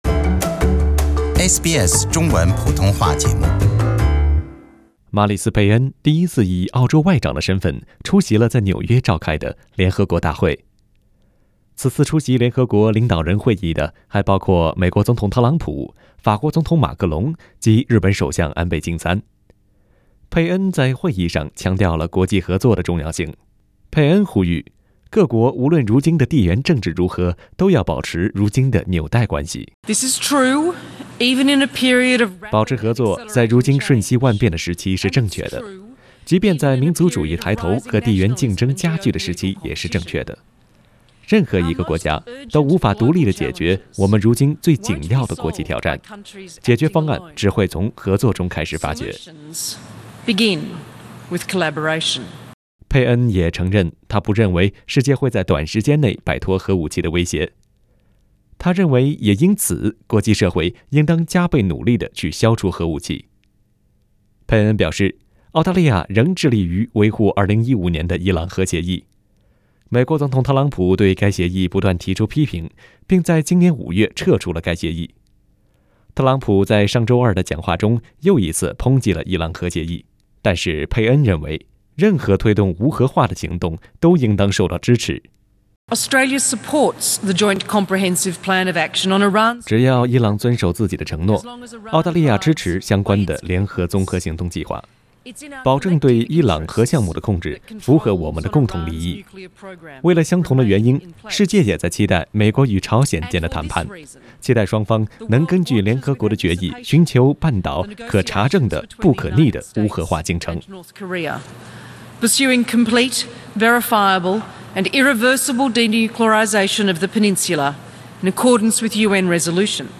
本台记者为您带来的详细报道。